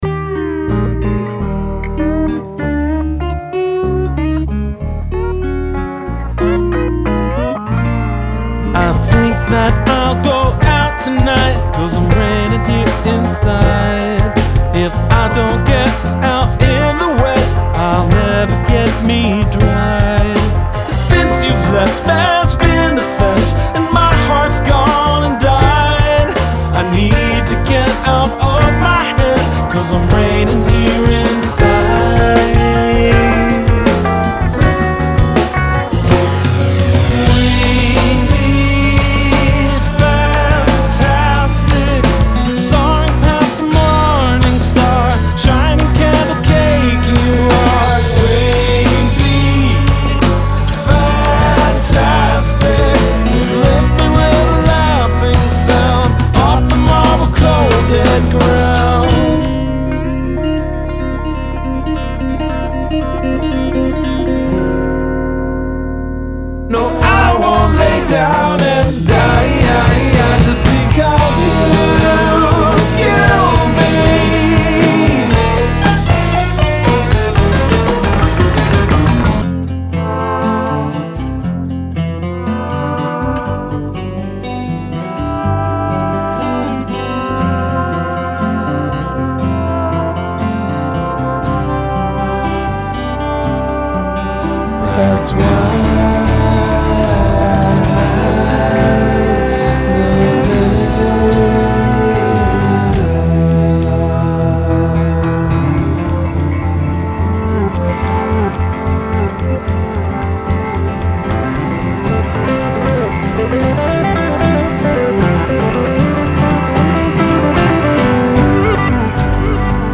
a collection of song snippets